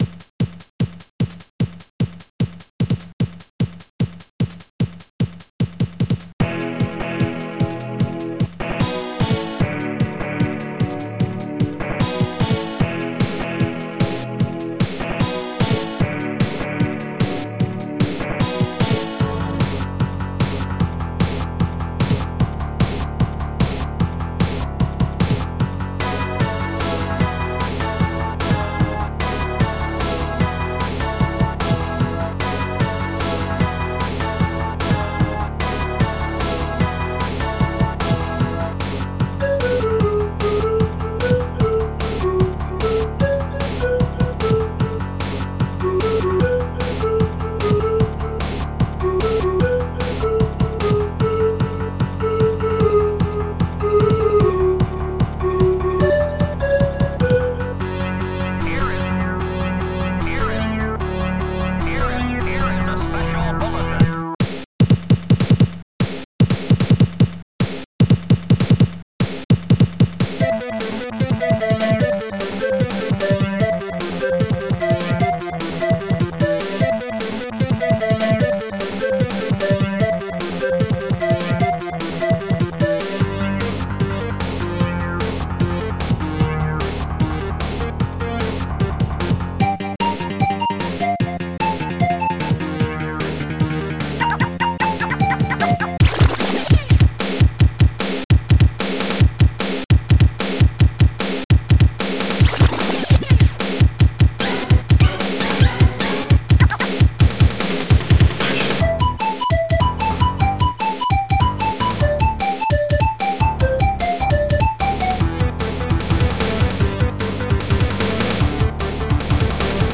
Protracker and family
st-07:bassdrum7
st-07:BassGuitar
st-04:HighHat2
st-08:elecguitar2
st-01:HallBrass